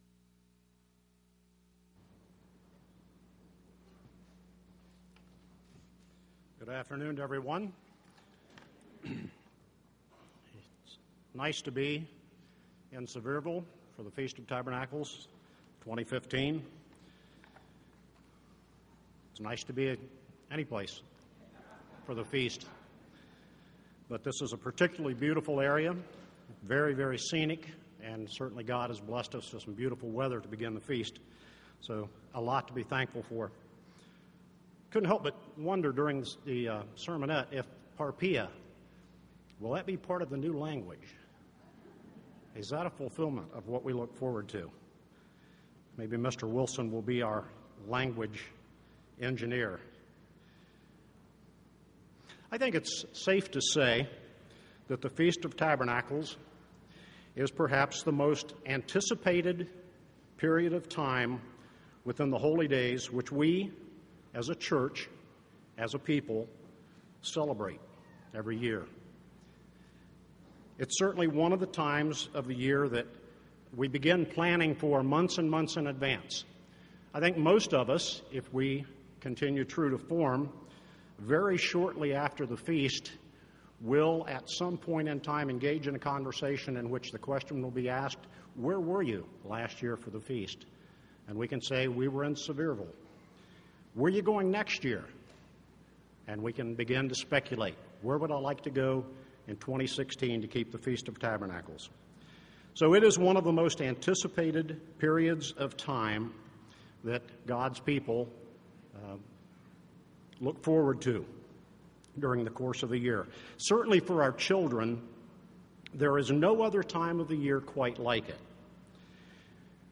This sermon was given at the Sevierville, Tennessee 2015 Feast site.